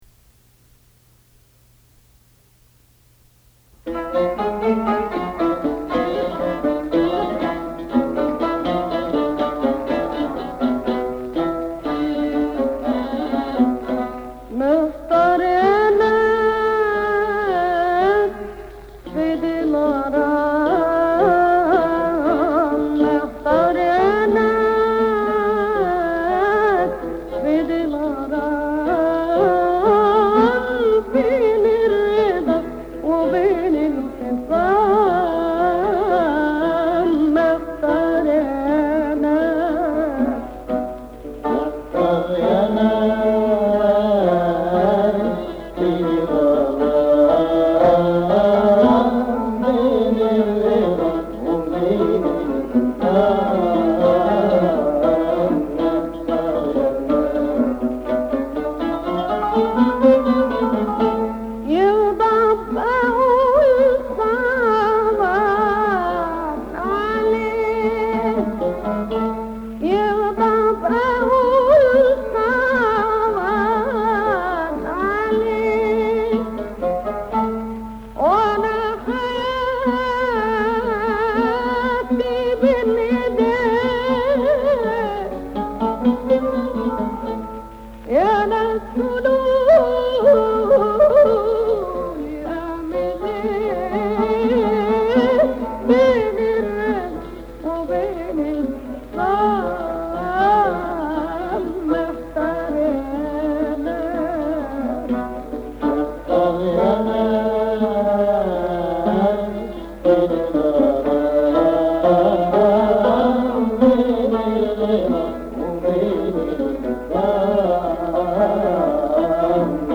Used here to illustrate an older Bayati Shuri pathway, in which the 7th scale degree is tonicized with Nahawand.
Maqam Bayati Shuri
Performer: Umm Kulthum
(Doulab intro)